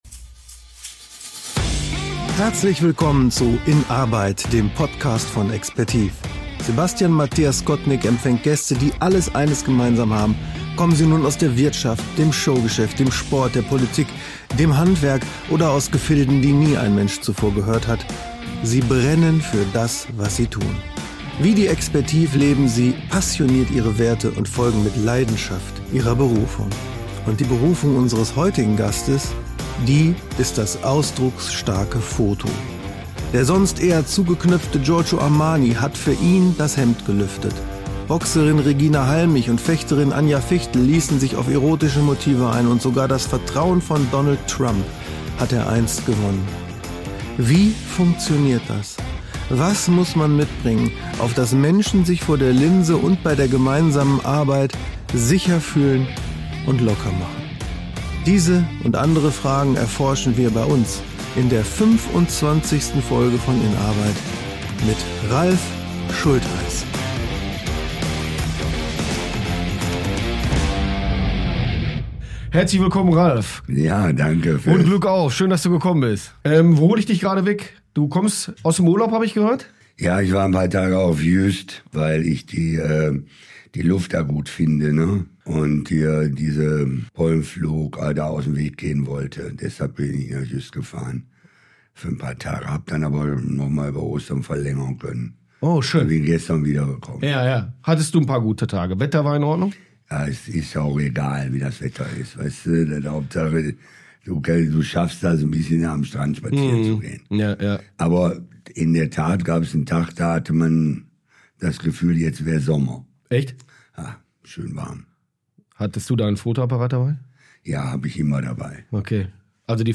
Die Technik hat ihn dabei stets weniger interessiert als die Kunst, selbst in kürzester Zeit eine Atmosphäre zu schaffen, in der das Gegenüber die Kamera vergisst und sich öffnet. Ein Gespräch über Können und Betrieb, die Bedeutung des Schicksals und die Wege, die sich ebnen, wenn man konsequent seine Freiheit auslebt.